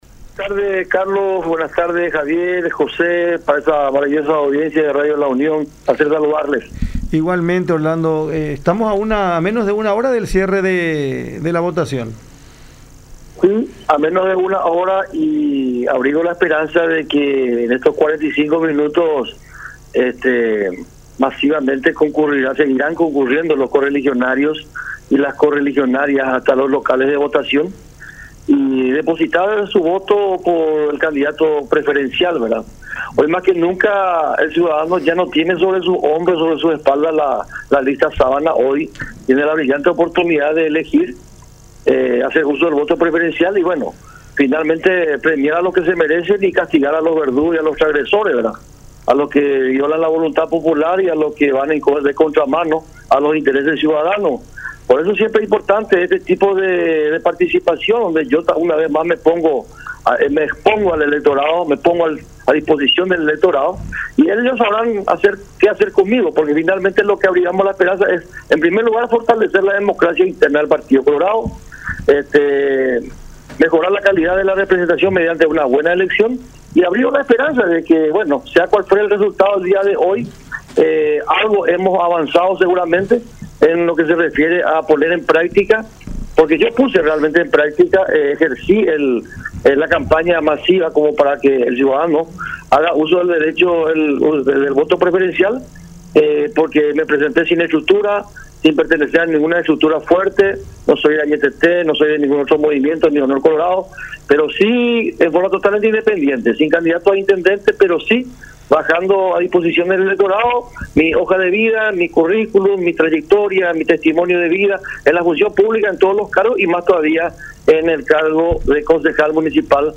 “Tenemos que unir a nuestro partido, no dividirlo. Debemos estar unidos y dejar de lado los incidentes y las provocaciones. Tenemos que pacificar y ser parte de la construcción y no de destrucción”, expresó el edil de la ANR en diálogo con La Unión, en alusión a las escaramuzas, por ejemplo, sucedidas en el Colegio Naciones Unidas del Barrio Obrero de Asunción, en horas de esta tarde.